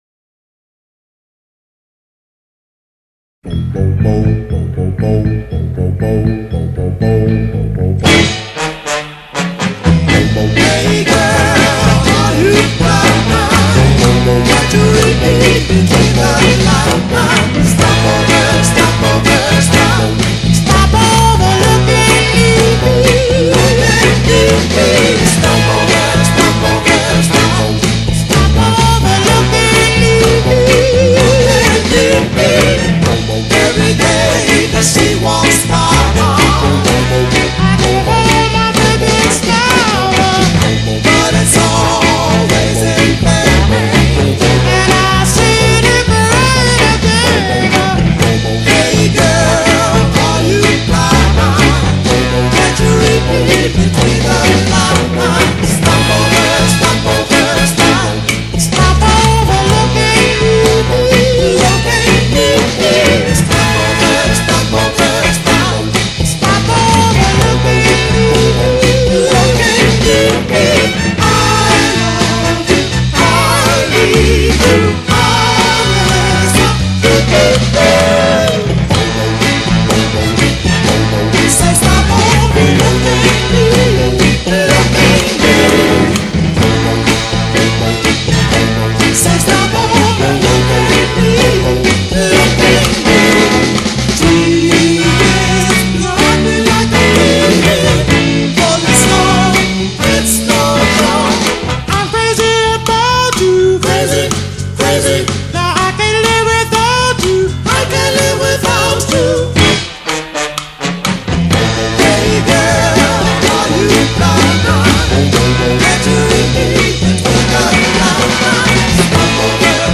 soul
peppy